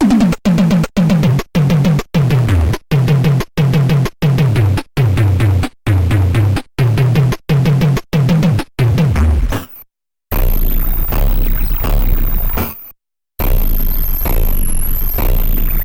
电瓶玩具猕猴桃 " 鼓的投掷
描述：从一个简单的电池玩具中录制的，是用一个猕猴桃代替的音调电阻！
Tag: 音乐学院-incongrue 电路弯曲 俯仰